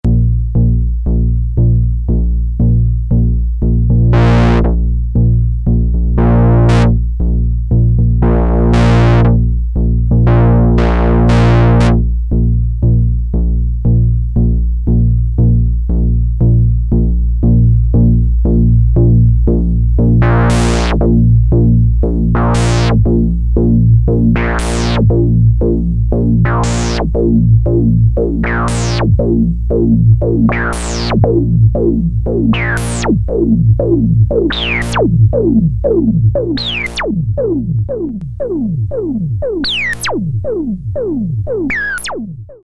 Changing filterQ from low to high while LFO modulating filterfrequency with a 2Hz sawtooth
filterq-demo1.mp3